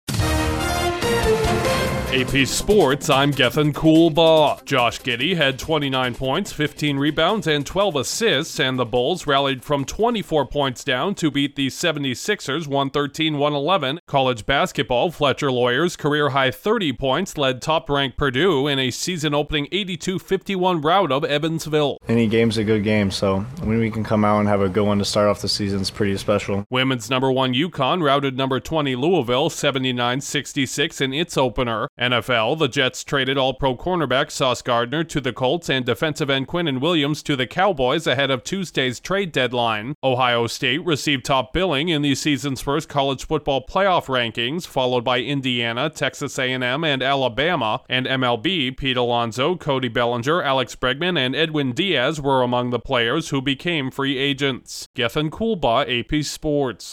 A triple-double powers Chicago’s comeback win over Philly in the NBA, the top teams in college basketball open their seasons with blowout victories, stars are dealt at the NFL trade deadline, Ohio State tops the first College Football Playoff rankings and notable MLB players elect free agency. Correspondent